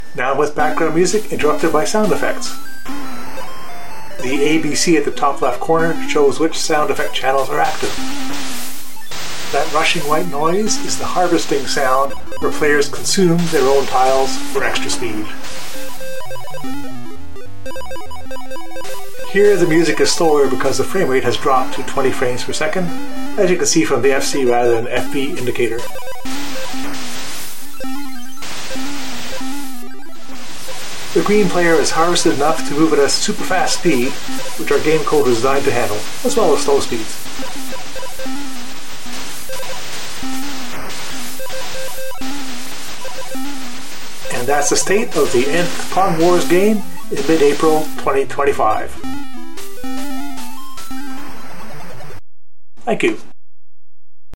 After a daunting delay to compose music (new topic for me), I gave up and just put in an alternating scales run with some percussion and concentrated on getting the code in the game.
[Video of Game with Music and Sound Effects]
sound and narration.